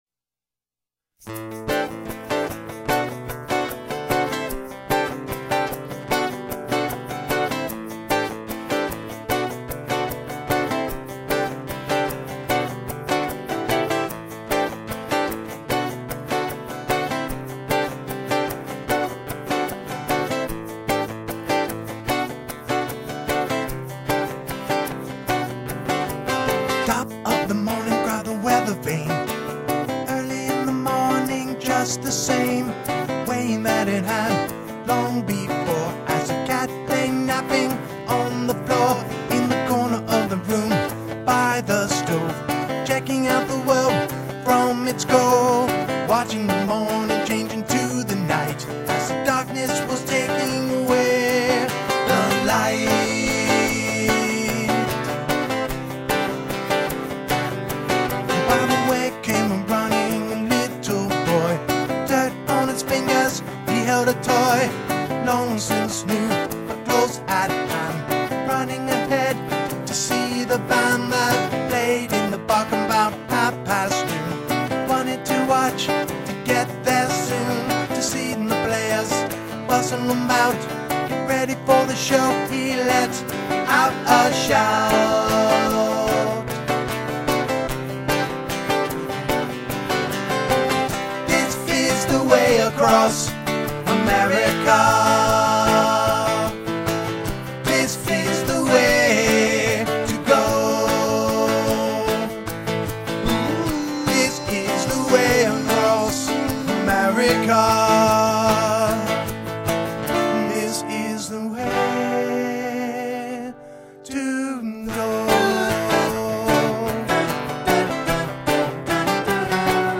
All Original Indy Rock Sound